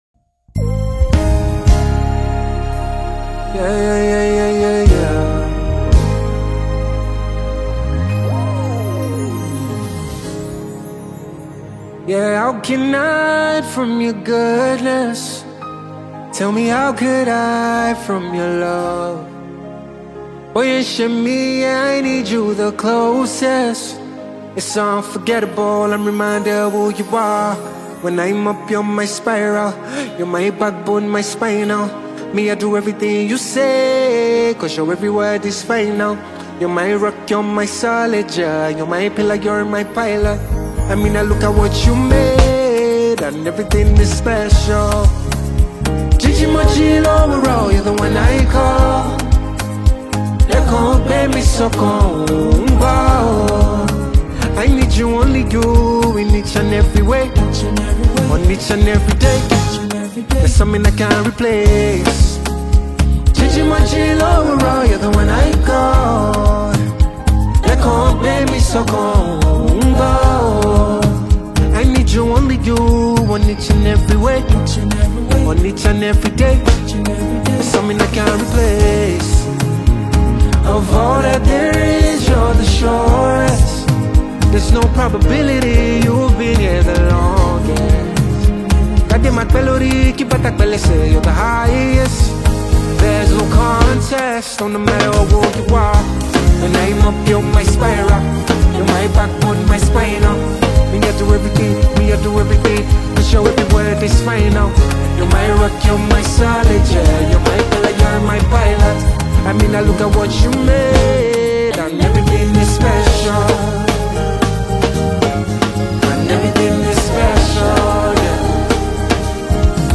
January 22, 2025 Publisher 01 Gospel 0